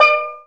SteelDrum_D5_22k.wav